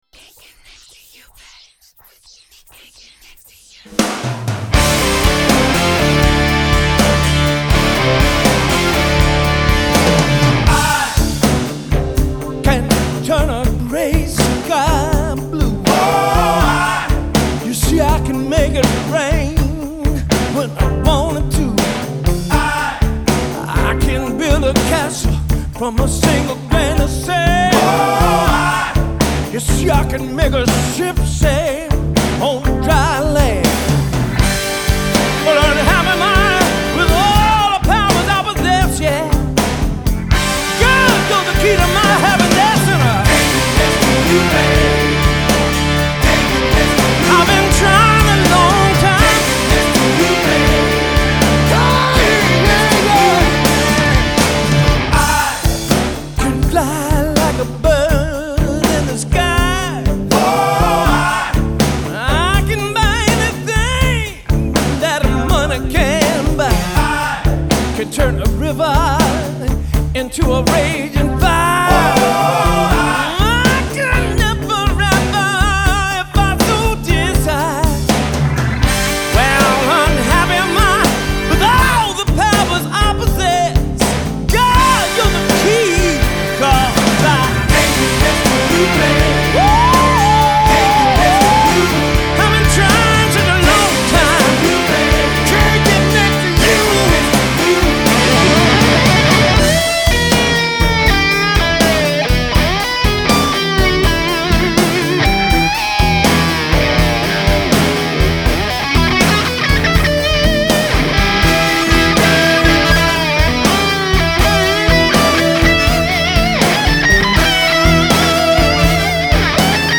Morceau Blues  en C mineur 7
Blues Rock FM Mar 30